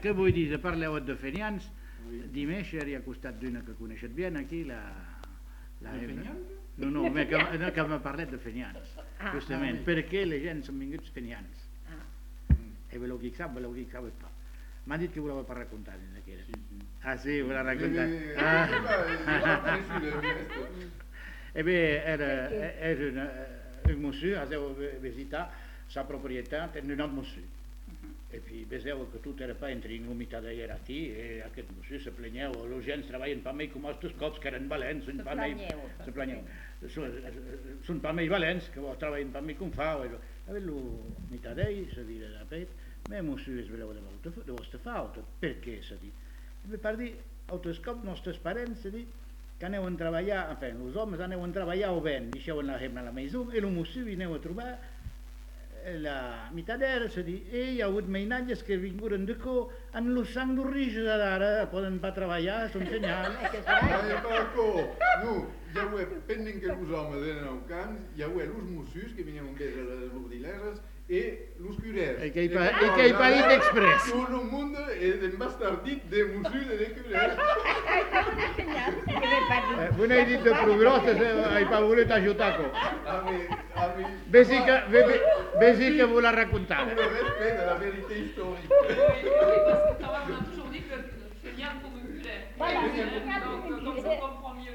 Lieu : Uzeste
Genre : conte-légende-récit
Effectif : 1
Type de voix : voix d'homme
Production du son : parlé
Classification : récit anecdotique